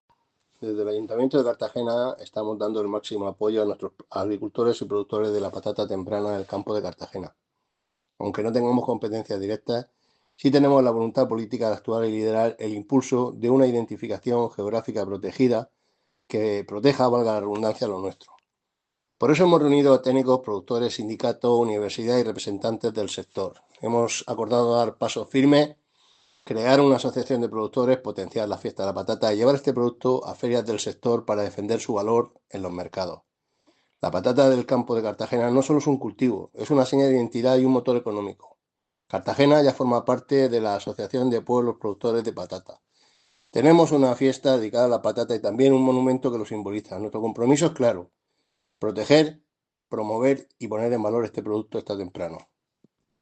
Enlace a Declaraciones de José Ramón Llorca.